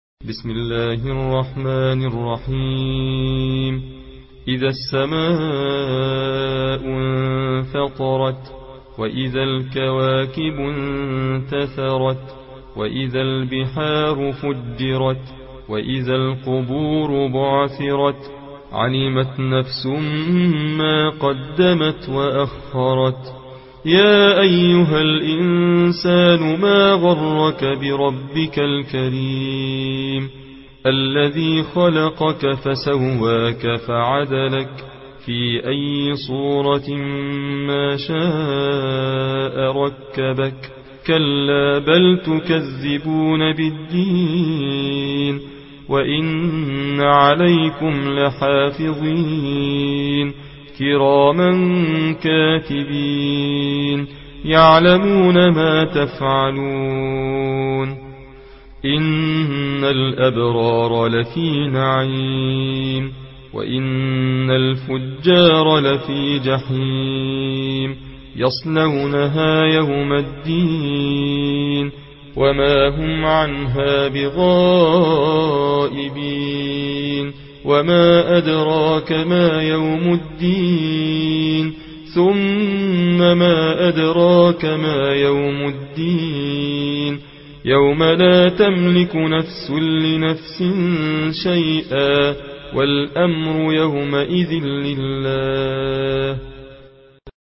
Riwayat Hafs an Asim